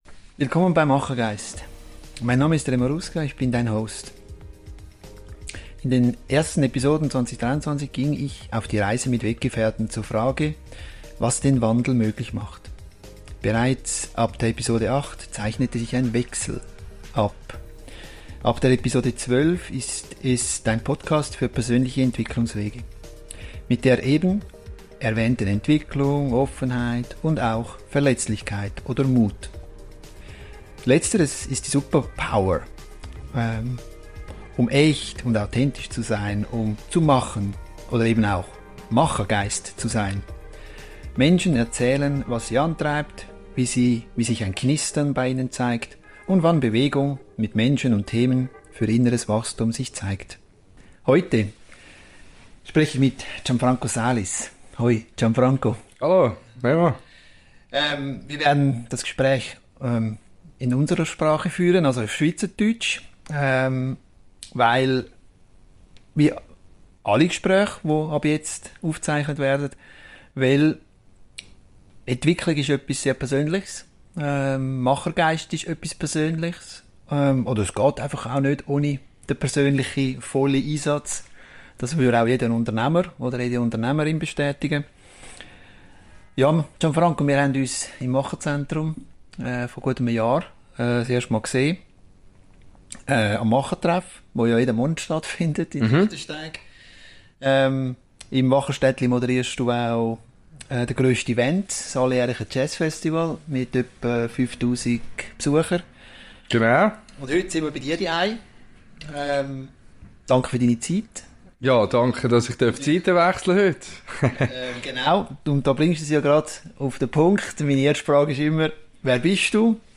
Beschreibung vor 2 Jahren Ein lebendiges Gespräch über Ego-Entwicklung mitten aus dem Leben.